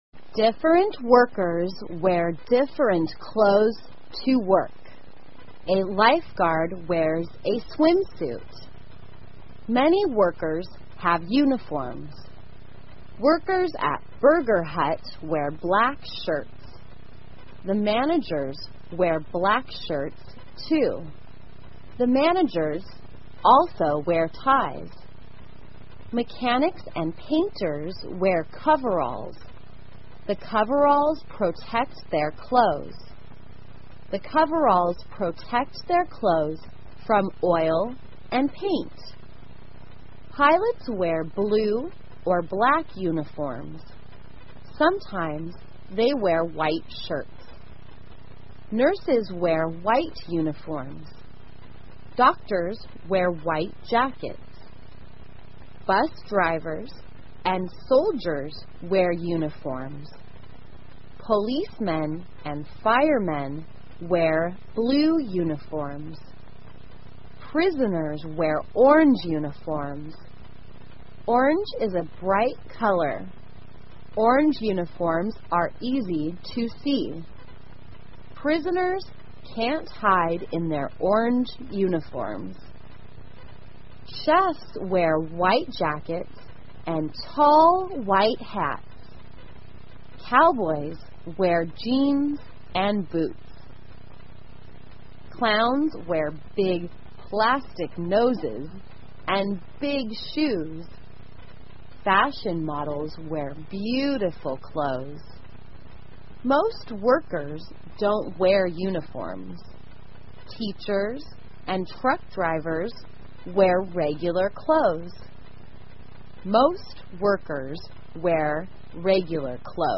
慢速英语短文听力 穿什么 听力文件下载—在线英语听力室